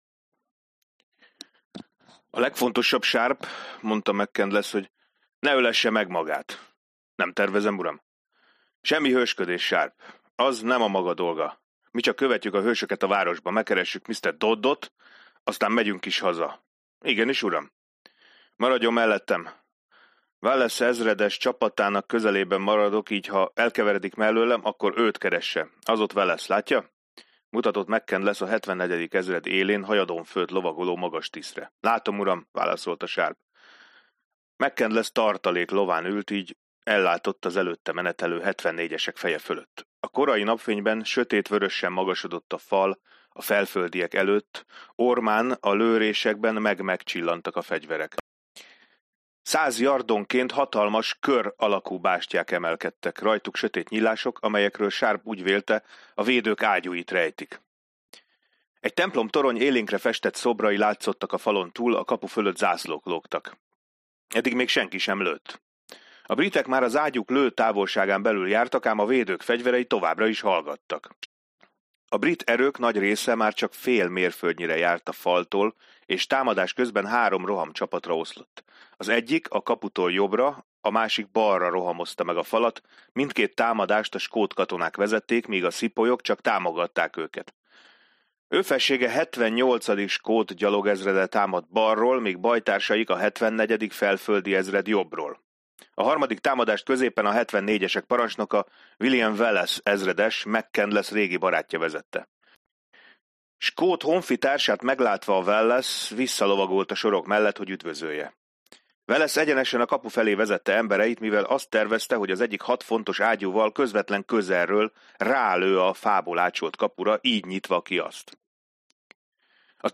Hangoskönyv